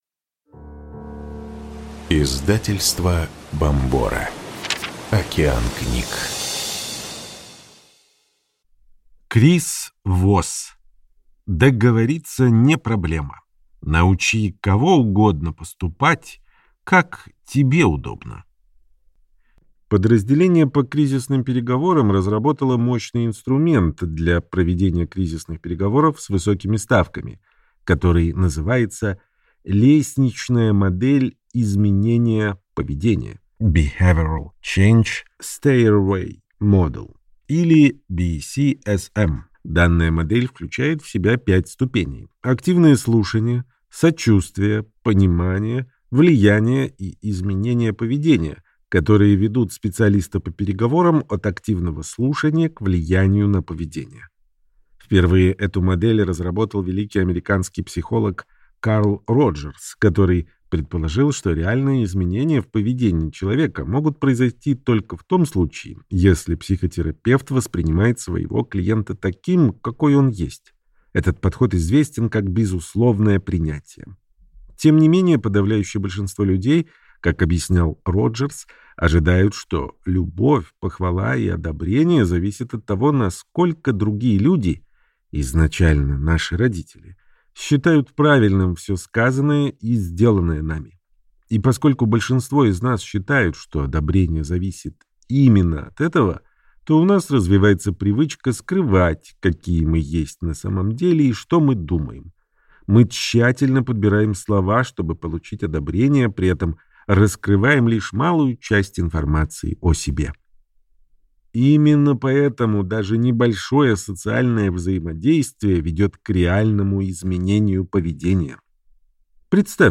Аудиокнига Договориться не проблема. Как добиваться своего без конфликтов и ненужных уступок | Библиотека аудиокниг